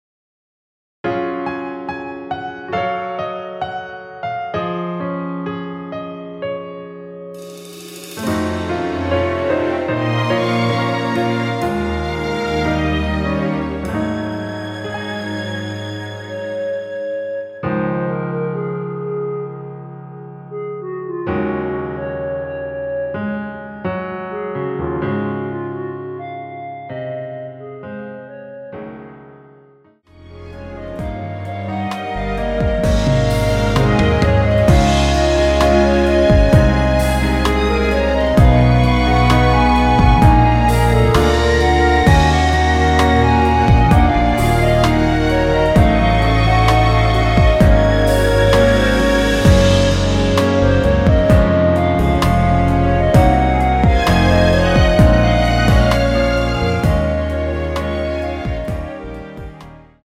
원키에서(-1)내린 멜로디 포함된 MR 입니다.
Db
노래방에서 노래를 부르실때 노래 부분에 가이드 멜로디가 따라 나와서
앞부분30초, 뒷부분30초씩 편집해서 올려 드리고 있습니다.
중간에 음이 끈어지고 다시 나오는 이유는